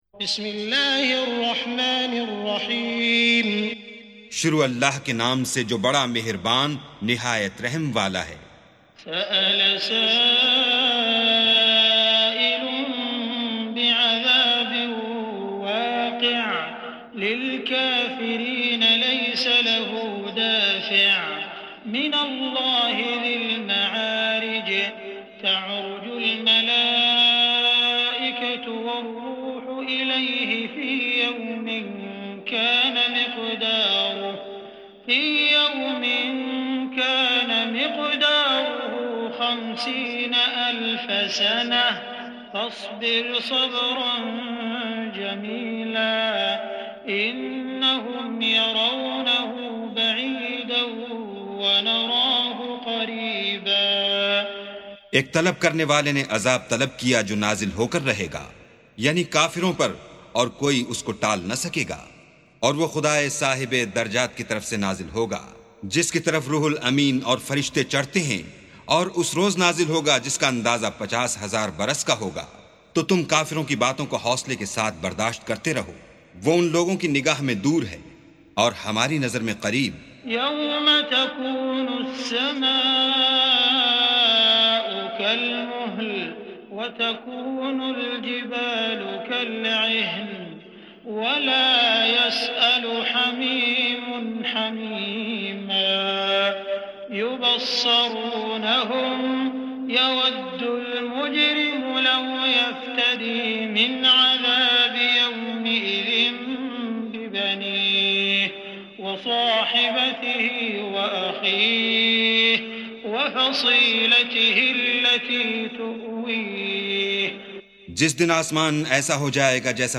سُورَةُ المَعَارِجِ بصوت الشيخ السديس والشريم مترجم إلى الاردو